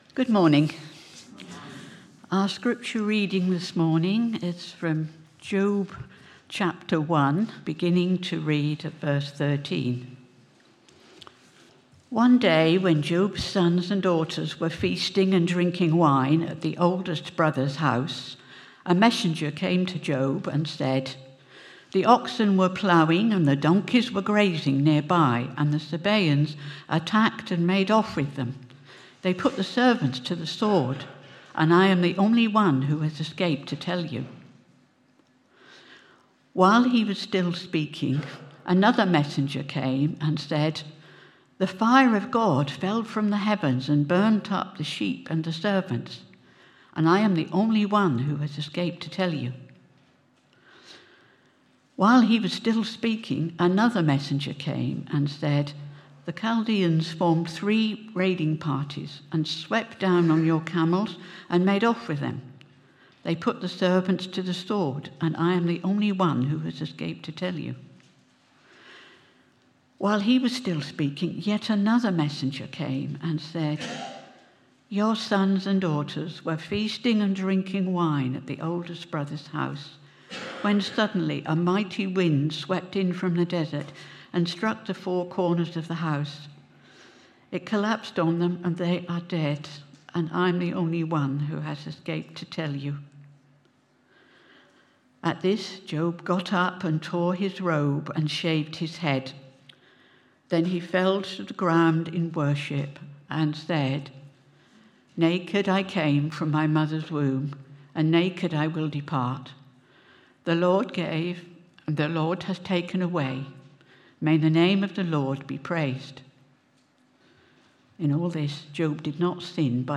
Media for Sunday Service on Sun 01st Jun 2025 10:00
Theme: In Suffering, Obedience, and Eternity Sermon